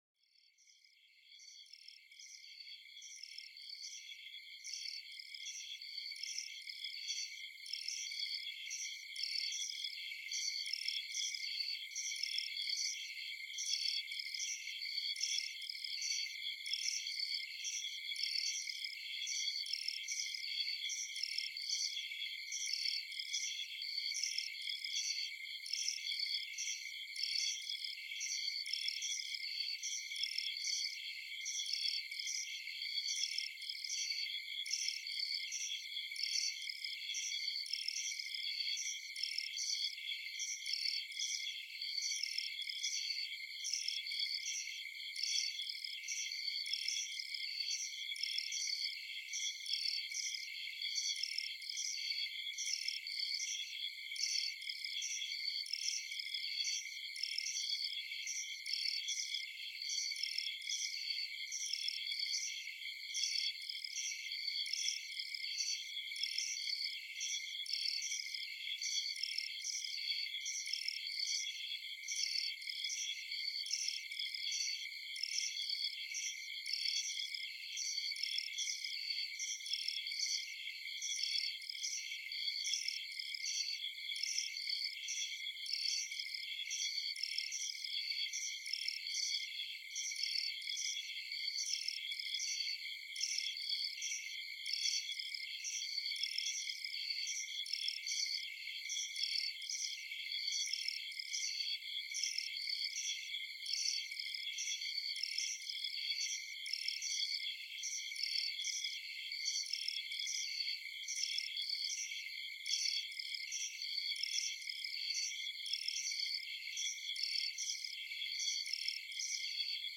Sons apaisants de la forêt – Bienfaits pour la relaxation